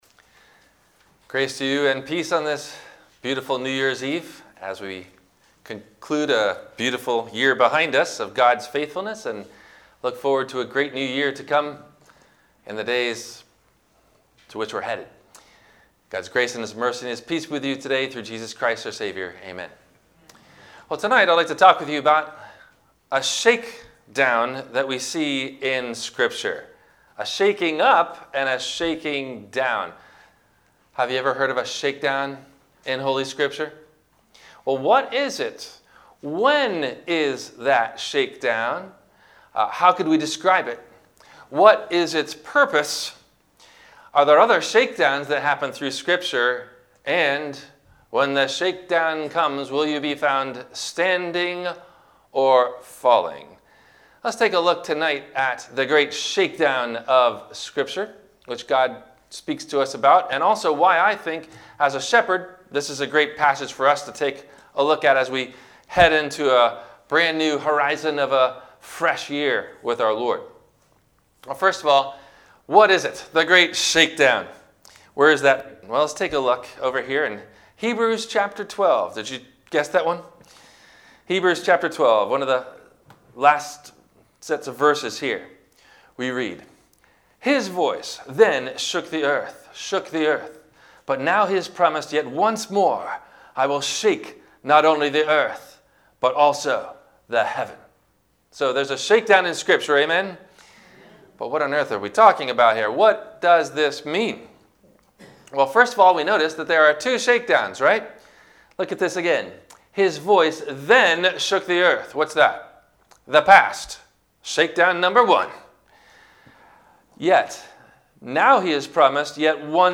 Sermon Archives - Christ Lutheran Cape Canaveral